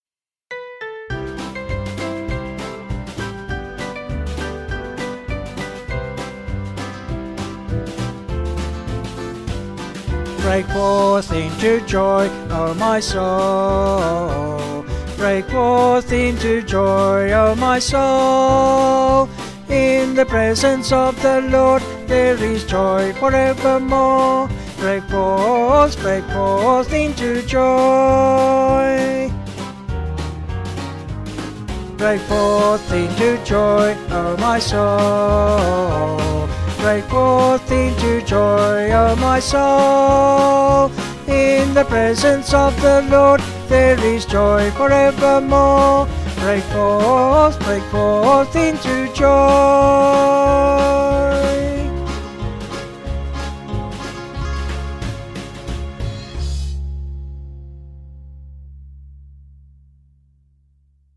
Vocals and Band   703.5kb